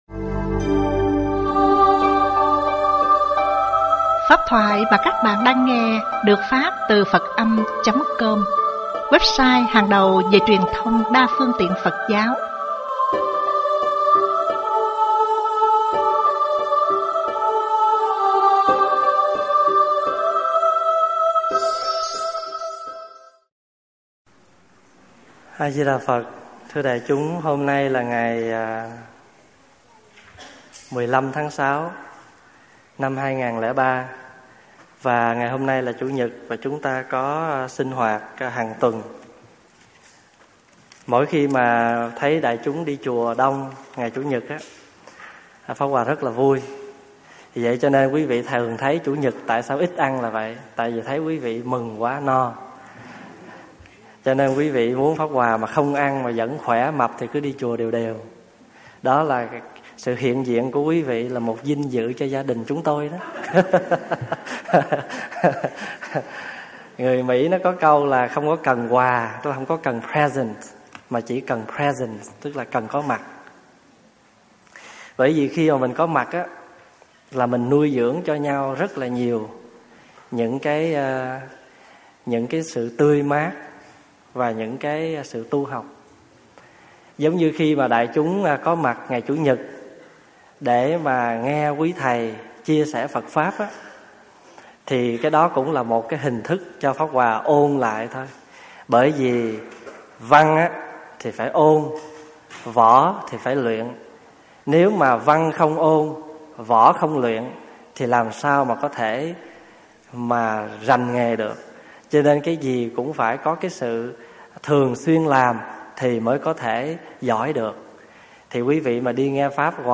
thuyết pháp Những Bước Thăng Trầm